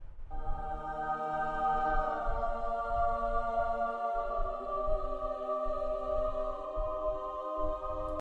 holy-sound.mp3